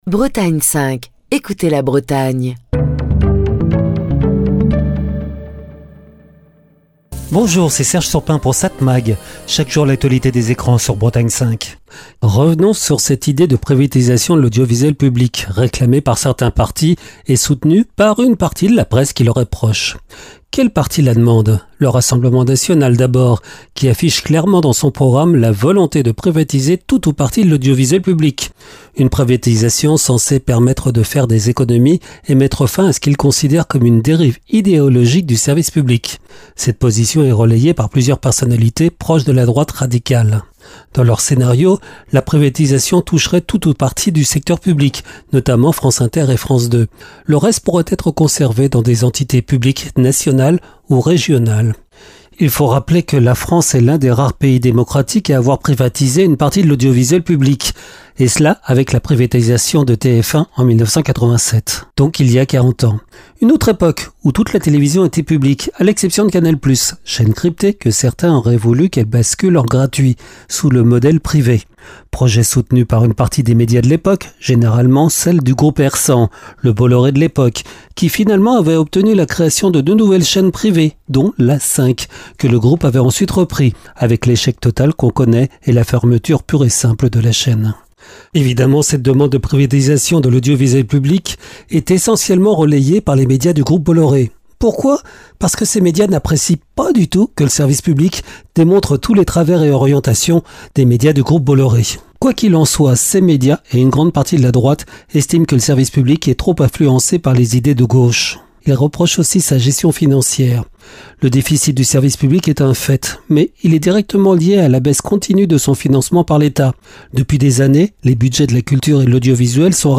Chronique du 18 novembre 2025.